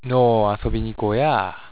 ためになる広島の方言辞典 な．
話し言葉の端々に使用され、最初・区切り・語尾につけると、それだけで広島弁っぽくなる。